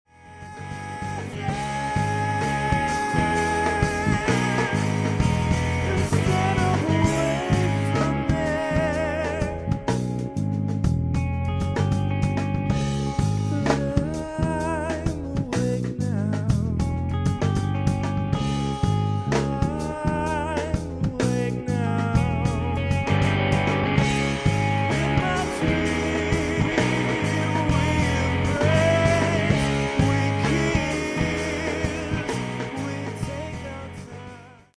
-Warmoth jazz bass copy with epoxied ebony neck.